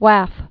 (wăf, wäf) Scots